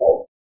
dogspacebark1.mp3